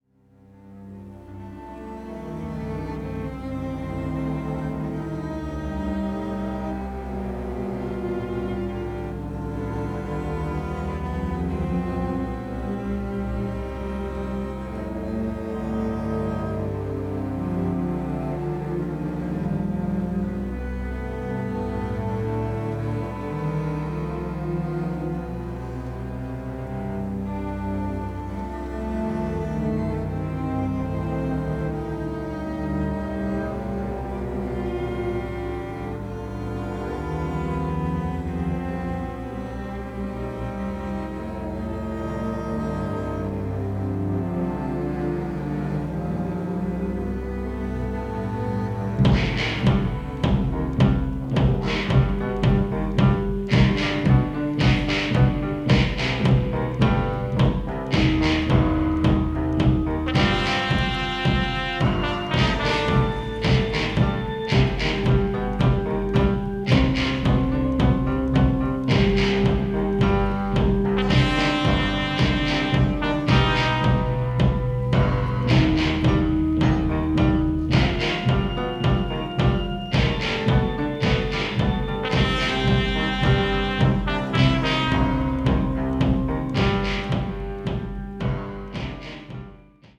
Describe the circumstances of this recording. The Original Album (stereo)